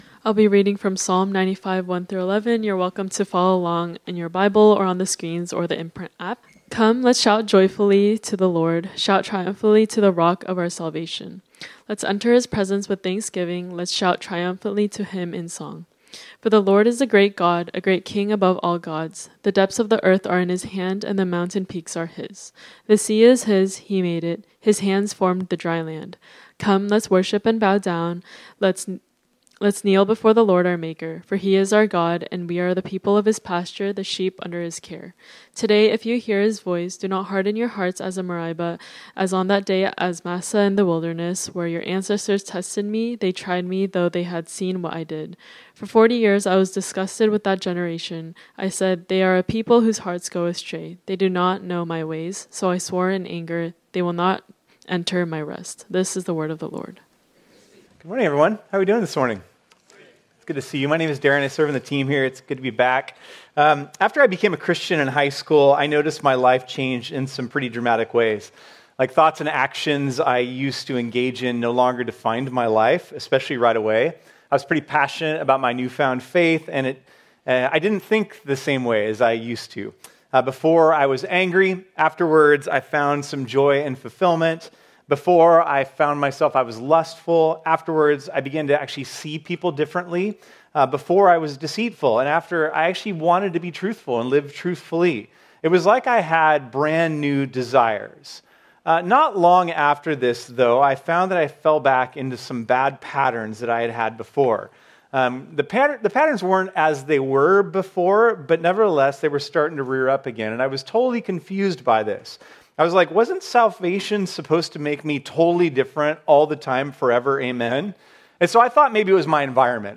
This sermon was originally preached on Sunday, March 2, 2025.